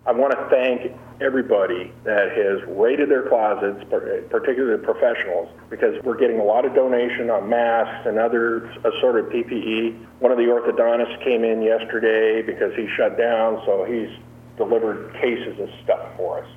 At a daily briefing Thursday morning, county officials reported there now have been 140 people tested in Clallam County, with one positive, 54 negative and 85 tests are pending.
Undersheriff Ron Cameron also said the local dental and medical community has responded to a shortage of personal protection equipment, by making donations from their own supplies.